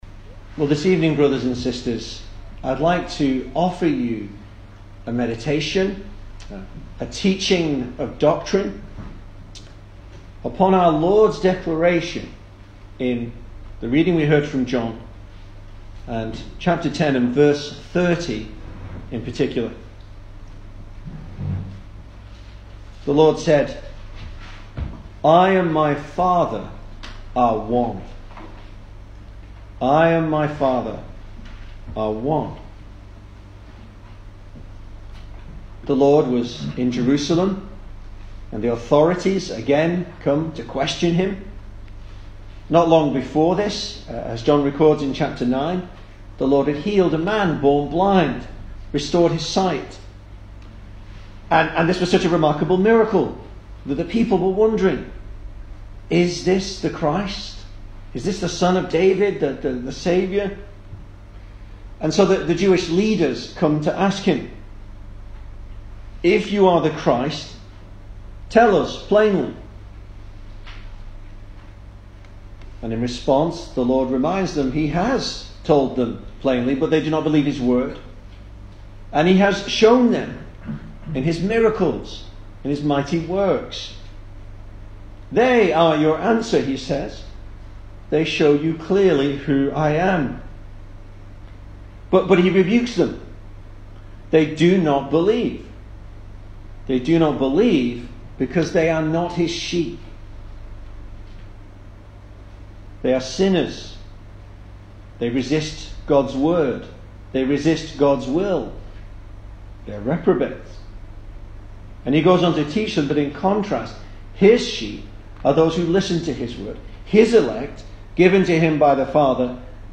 Service Type: Sunday Evening
Single Sermons Topics: The Holy Trinity